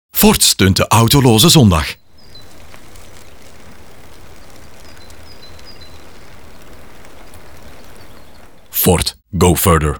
A l’occasion de la journée sans voiture ce 22 septembre, Ogilvy Bruxelles a conçu deux top topicals pour Ford - l’un radio, l’autre outdoor. Dans un court spot de 10’’ diffusé sur Studio Brussel et MNM, Ford soutient ainsi le Dimanche Sans Voiture avec 10’’ de silence.